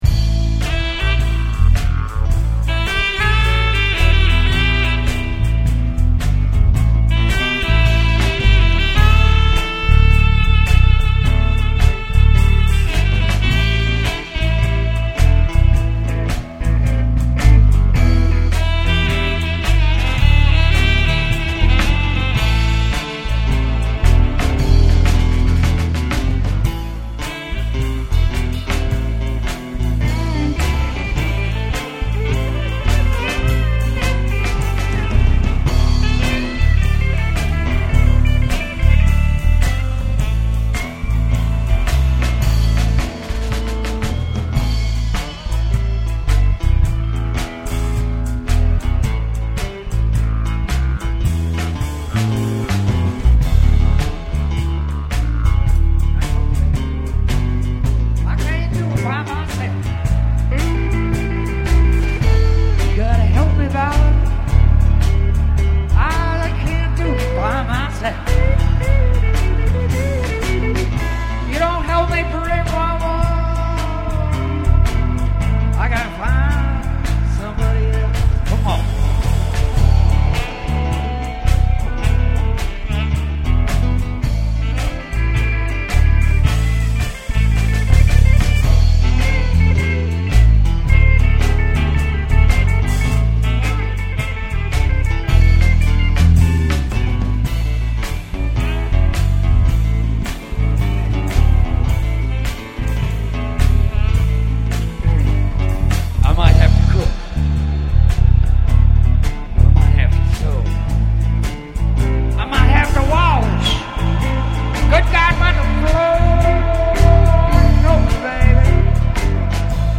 The third song is the result of another late night jam session at Star Bottling studios.
saxaphone
mic'ed acoustic guitar
Mr. K Blues, is a midwest version of electric blues.
This song was performed and recorded live in the Star Bottling studio. No overdubs were done.